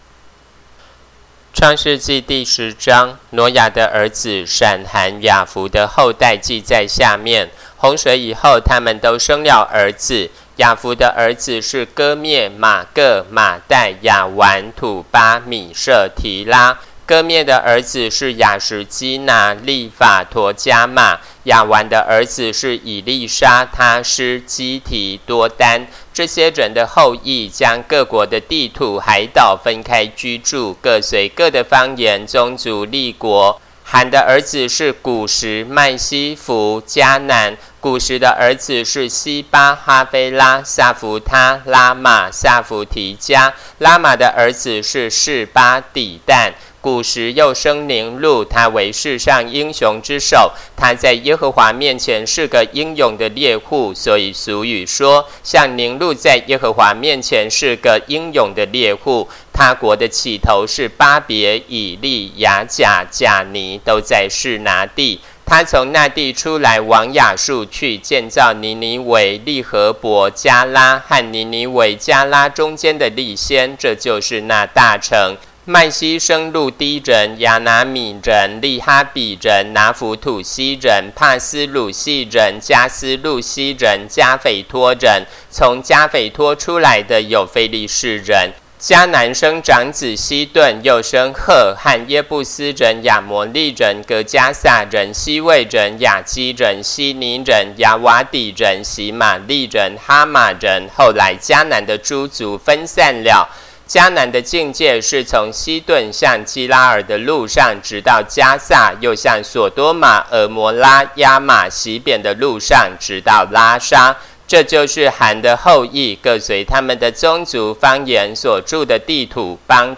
Spring 版和合本有聲聖經